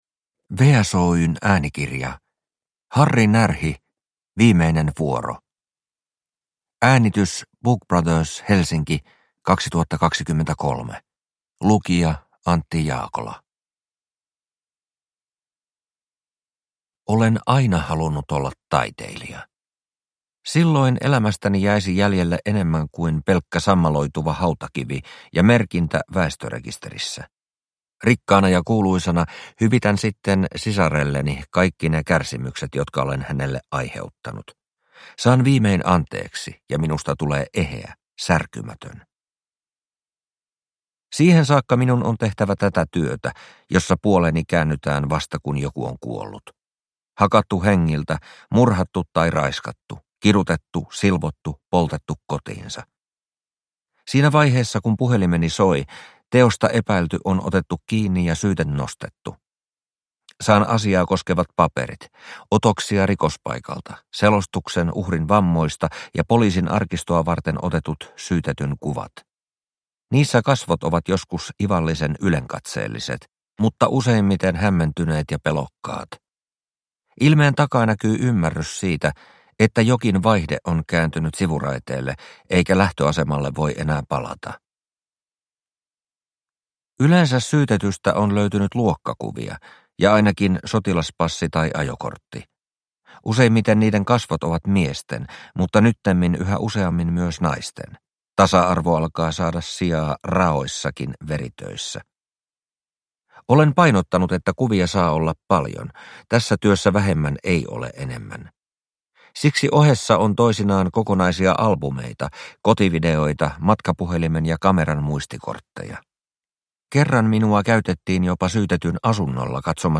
Viimeinen vuoro – Ljudbok – Laddas ner